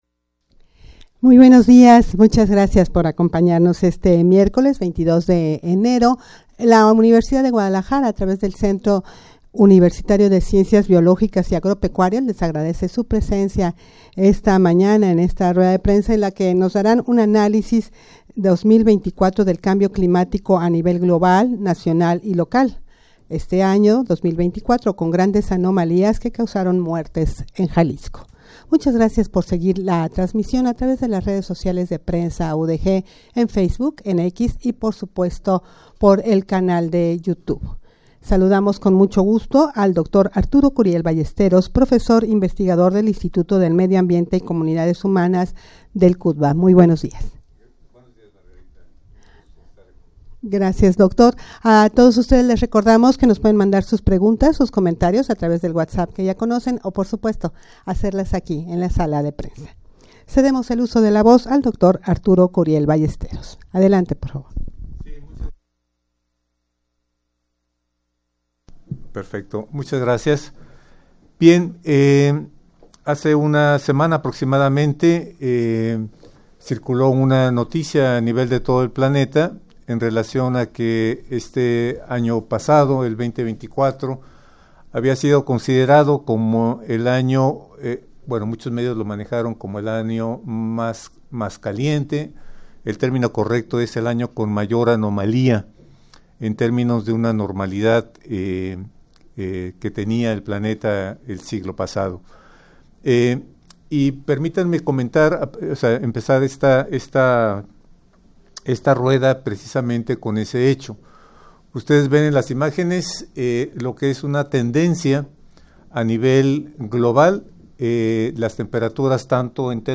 Audio de la Rueda de Prensa
rueda-de-prensa-analisis-2024-del-cambio-climatico-a-nivel-global-nacional-y-local.mp3